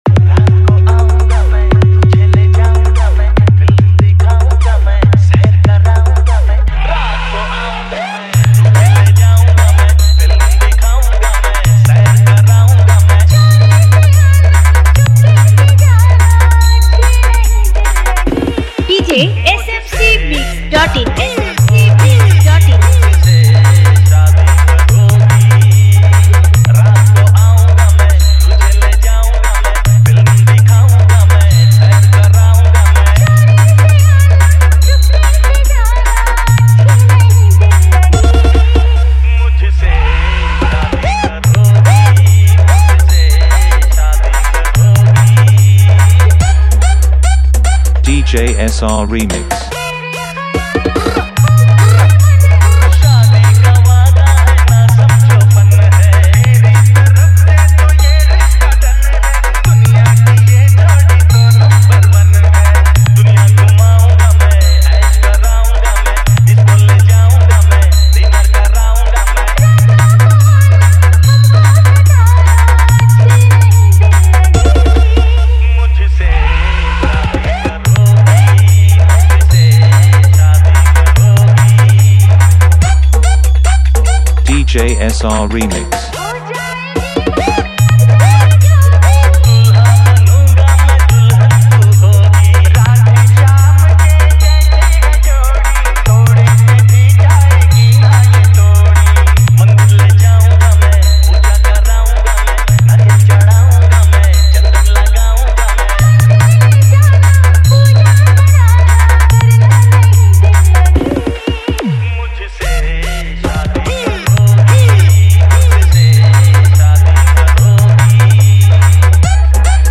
পুজোর সেরা ডিজে বাংলা গানের Pop Bass Humming মিক্স